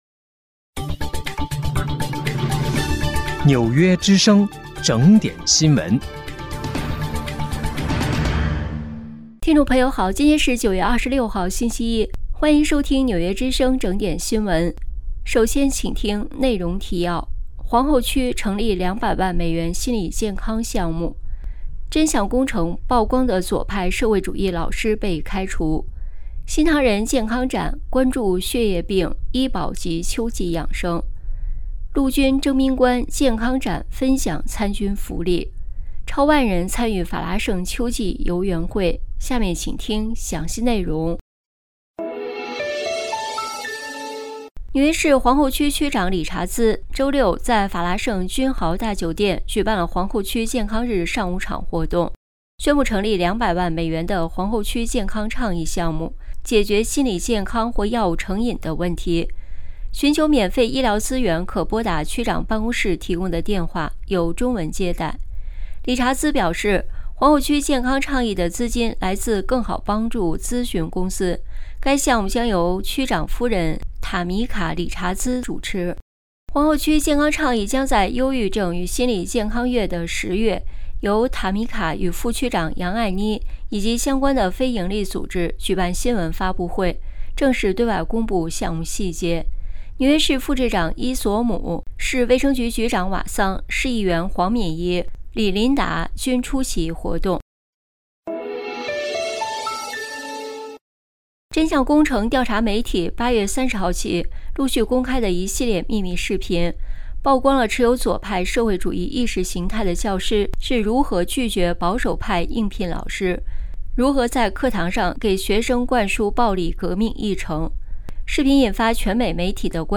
9月26号（星期一）纽约整点新闻